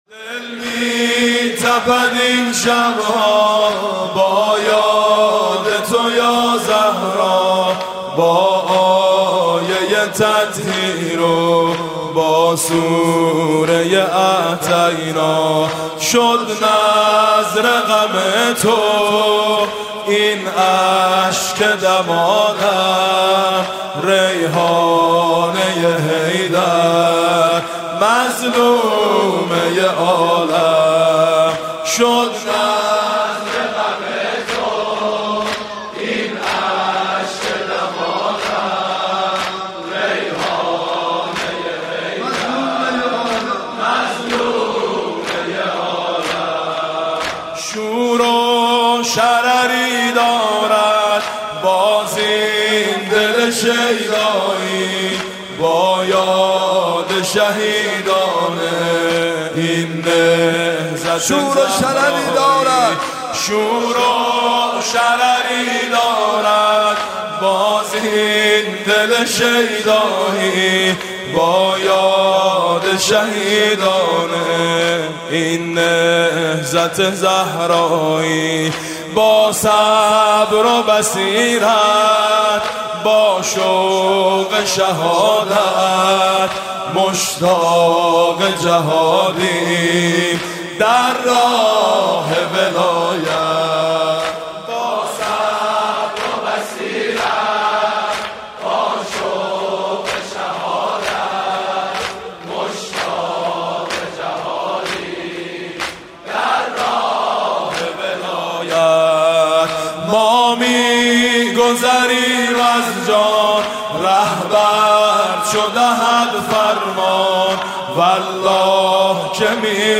مداحی و نوحه
سینه زنی، شهادت حضرت فاطمه زهرا(س